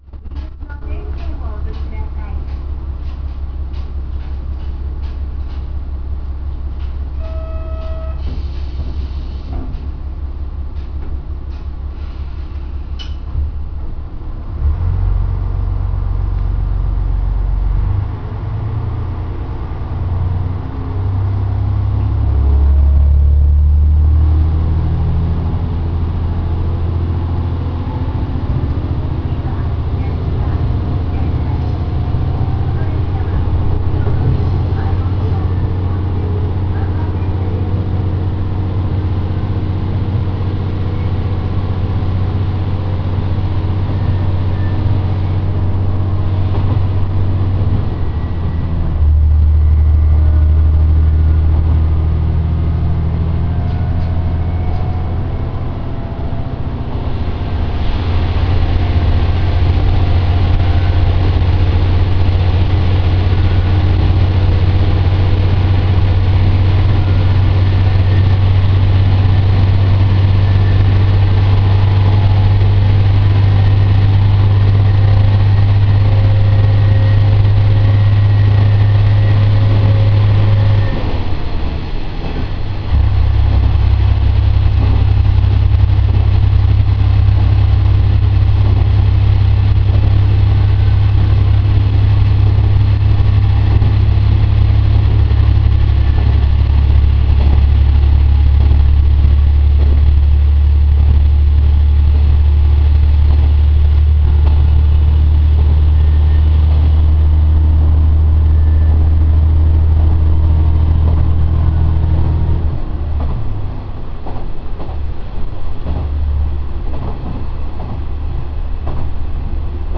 ・1000形走行音
【土佐くろしお鉄道ごめん・なはり線】田野→安田（3分18秒：1.05MB）
毎度ながらwikipedia丸写しですが、駆動機関はコマツ製の直列6気筒ディーゼル機関 (SA6D125-H) を用いているそうです。エンジンには詳しくないからさっぱりわかりませんが、ごめん・なはり線は線形が良いので割と飛ばすようで、豪快な音が録れます。